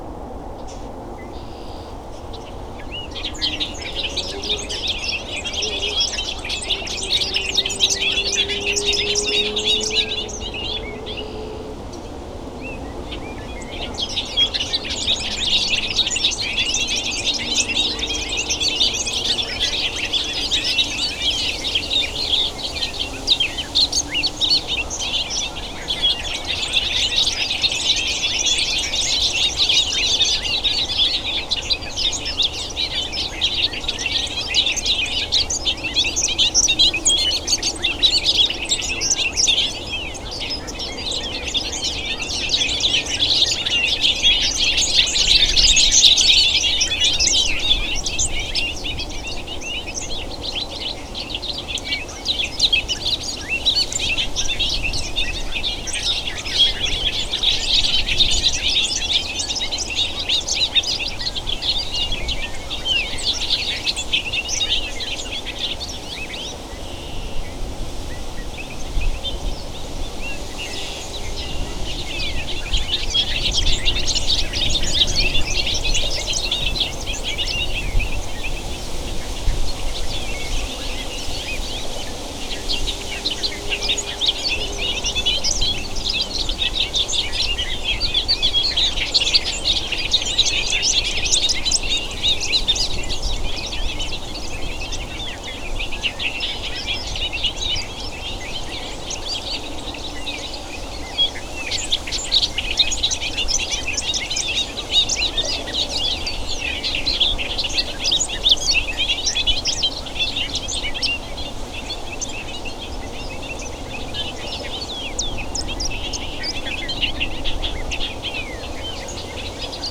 Bobolinks
As I was about to finish birding there and hop into my truck, I heard some singing that stopped me in my tracks.
Here’s how they sounded (I returned the next day with my recording gear).
bobolinks-50921.wav